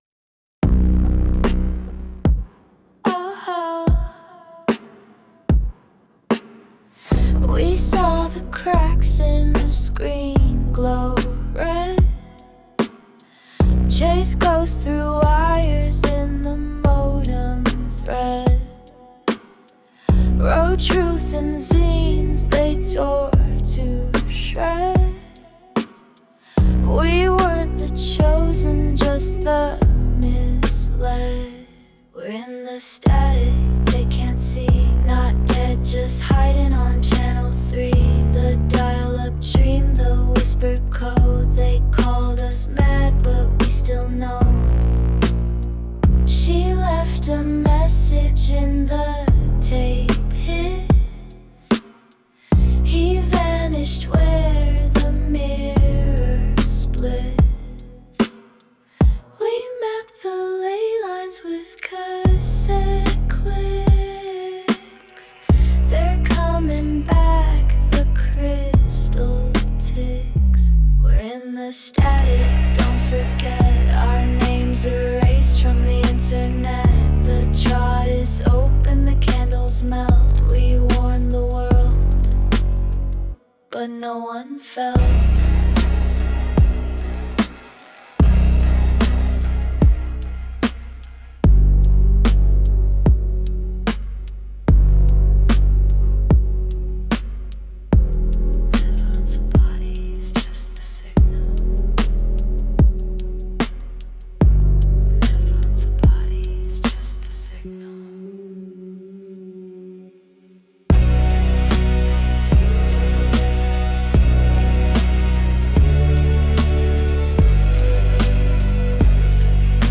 Seven fragments, static, recursion — a ritual in the noise.
Static, recursion, ritual.